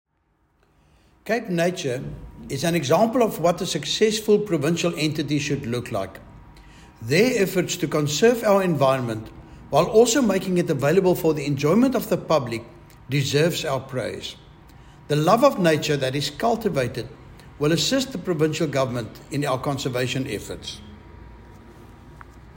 English soundbites from MPP Andricus van der Westhuizen attached.